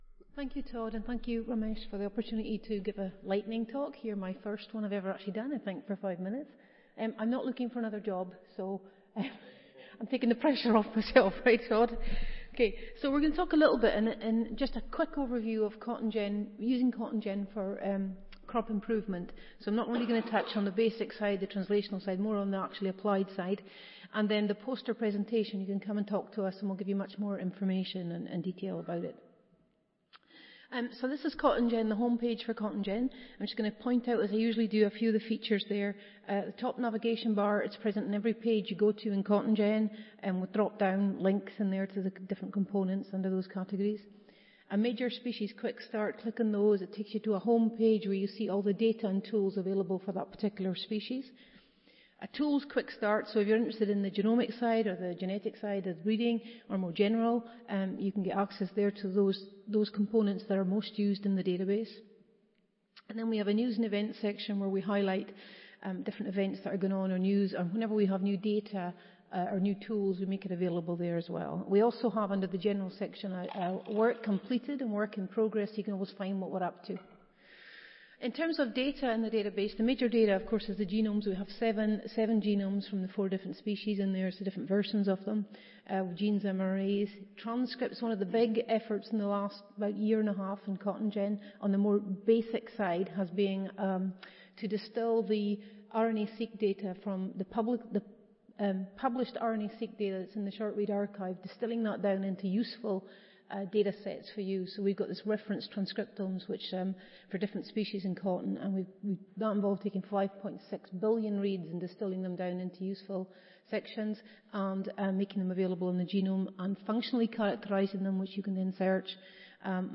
Lightning talks tied to a poster
Audio File Recorded Presentation